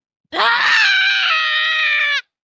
aaaaghhh gold ship cut Meme Sound Effect
Category: Anime Soundboard
aaaaghhh gold ship cut.mp3